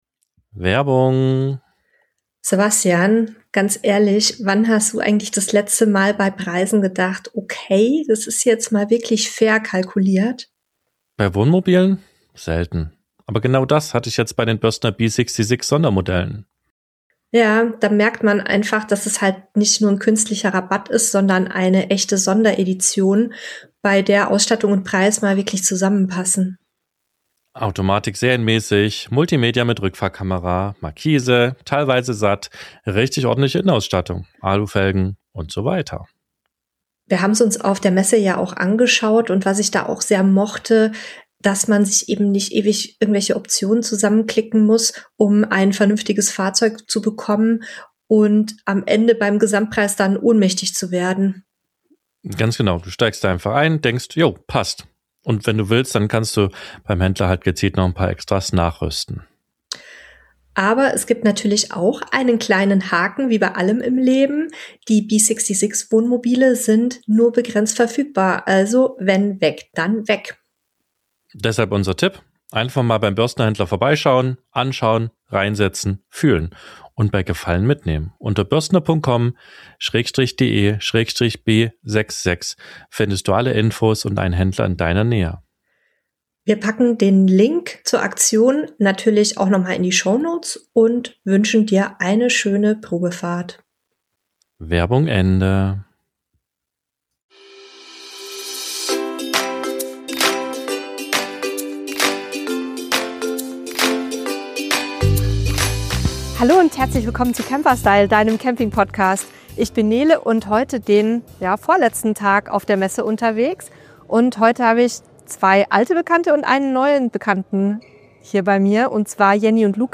Wir nehmen dich ein vorletztes Mal live mit auf die CMT nach Stuttgart